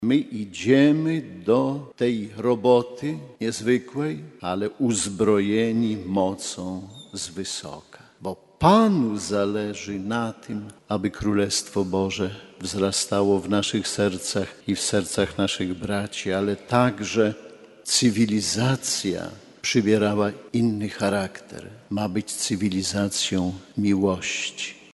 Centralnym Puntem była uroczysta msza św., której przewodniczył bp Romuald Kamiński. W homilii duchowny zachęcał do współpracy z Bożą łaską w dziele przemiany świata.